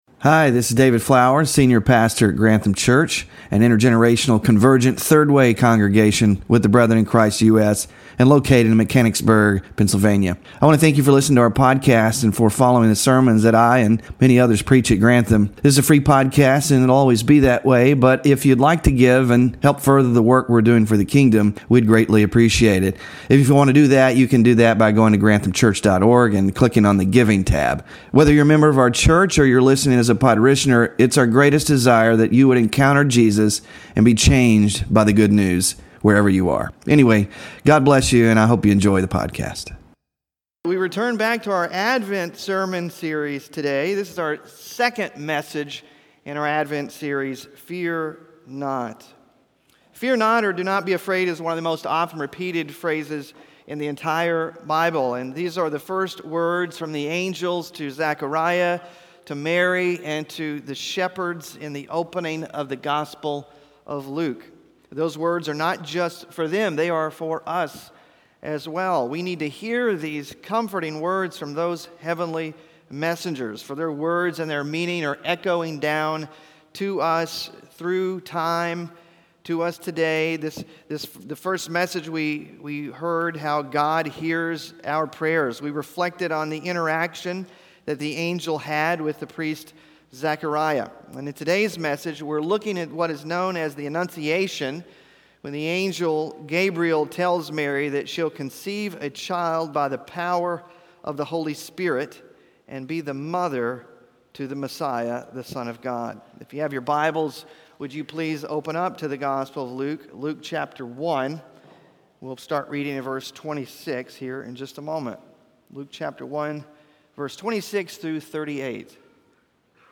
What does this news to a young peasant girl from a backwater village say about God’s favor and his ways of blessing the humble? In the third Sunday of Advent